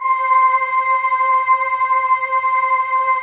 Index of /90_sSampleCDs/USB Soundscan vol.28 - Choir Acoustic & Synth [AKAI] 1CD/Partition C/09-GREGOIRE